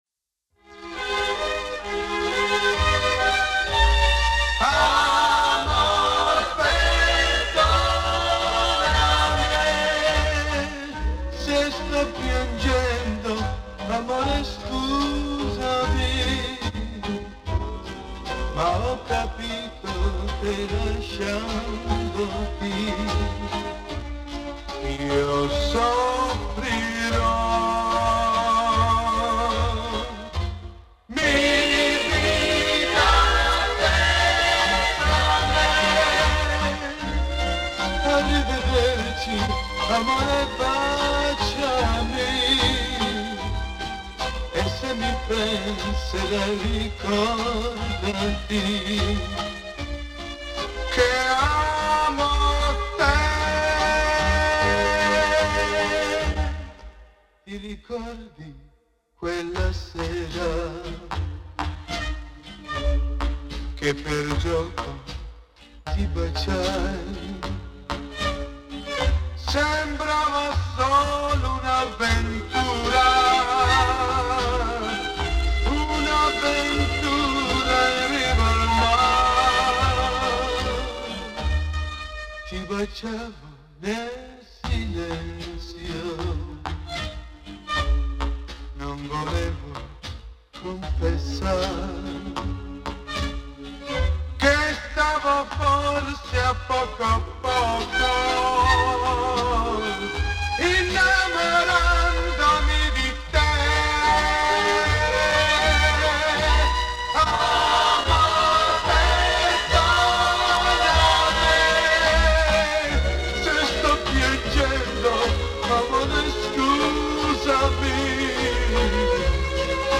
2. Вот запись с эфира радио: